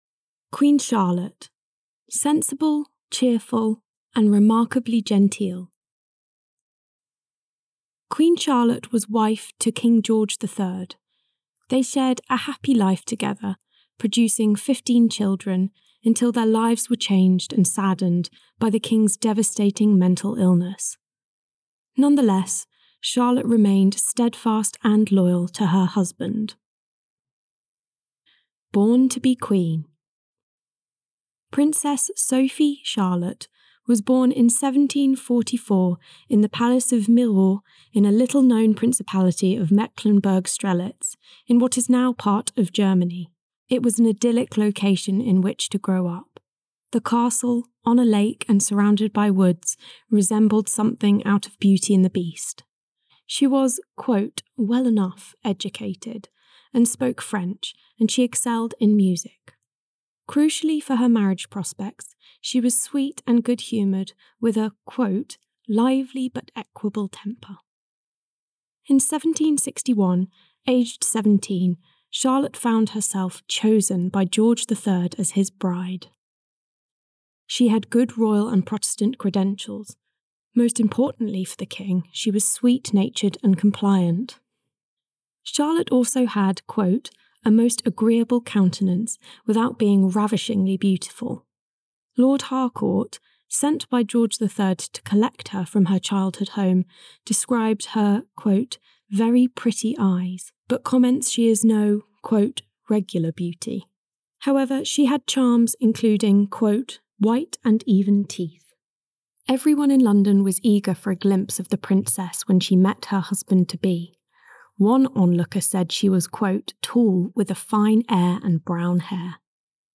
Listen to the contents of this page read by our team here, or keep scrolling to read about Queen Charlotte's life with images.